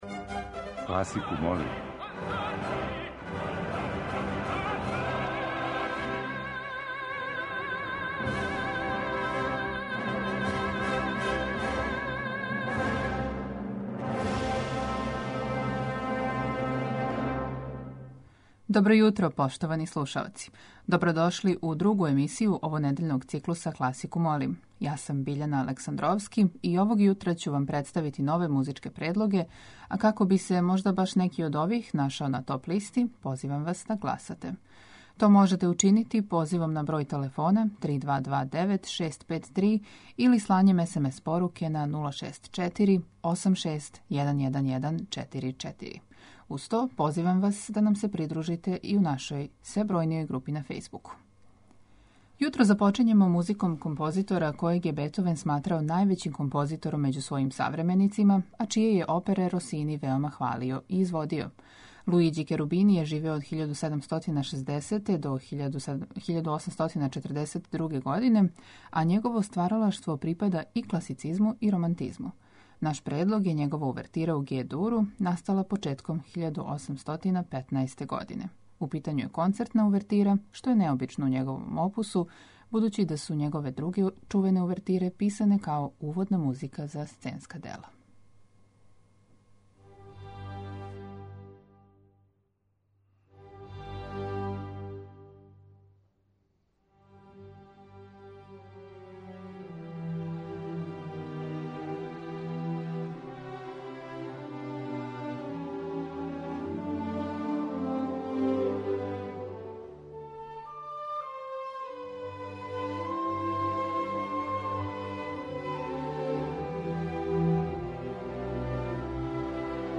Класику, молим, уживо вођена емисија, разноврсног садржаја, окренута је широком кругу љубитеља музике, а подједнако су заступљени сви музички стилови, епохе и жанрови.